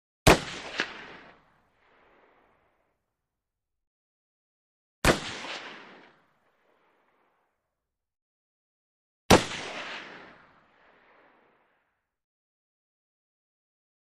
Mauser Rifle: Single Shots with Slap back ( 3x ); Three Single Shots. Loud Sharp Shots With Heavy, Long Echo. Close Up Perspective. Gunshots.